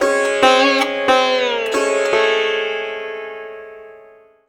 SITAR LINE17.wav